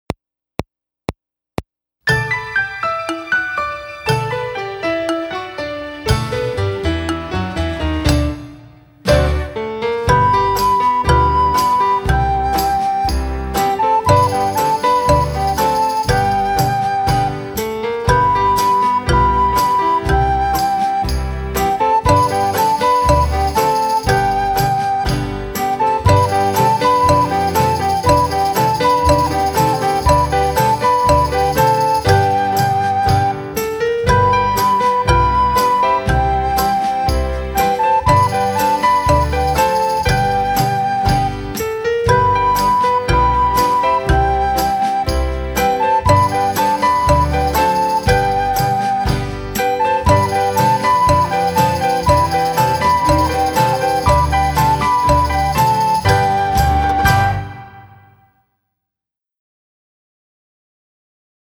Voicing: Recorder Collection